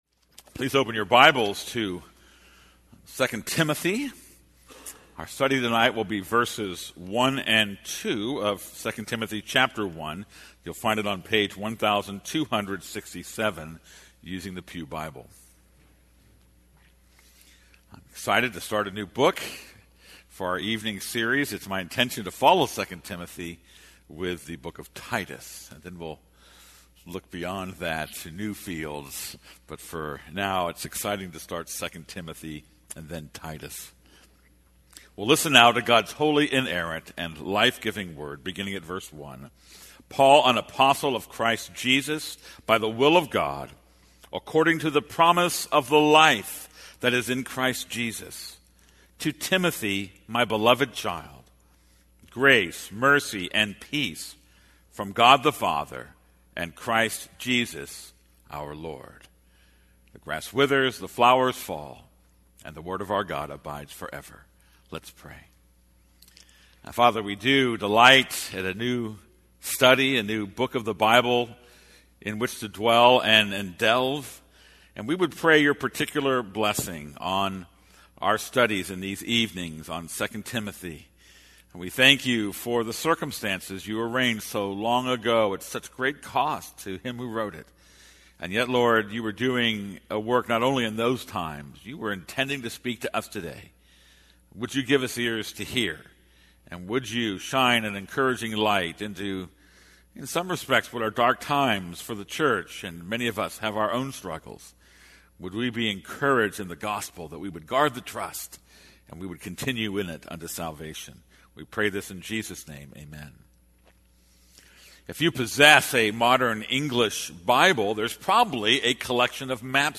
This is a sermon on 2 Timothy 1:1-2.